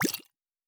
Potion and Alchemy 04.wav